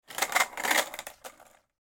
دانلود آهنگ اسکیت 1 از افکت صوتی حمل و نقل
جلوه های صوتی